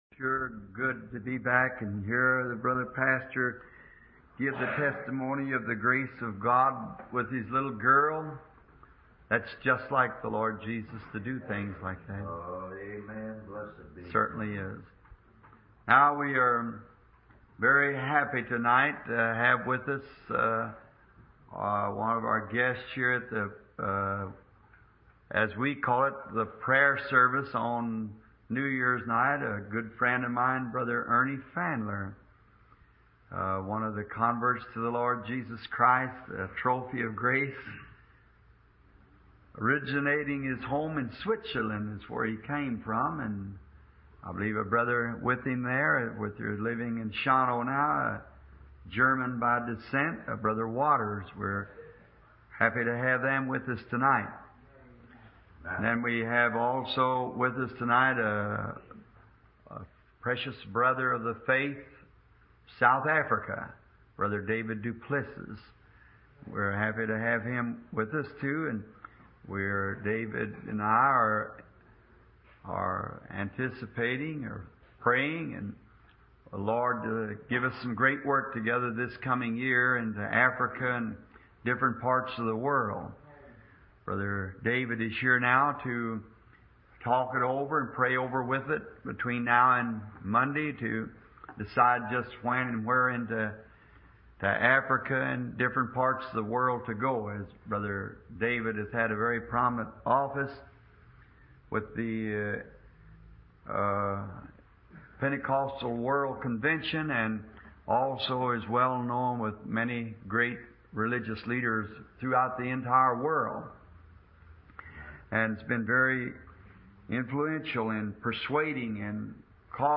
Dieses Portal gibt Ihnen die Möglichkeit, die ca. 1200 aufgezeichneten Predigten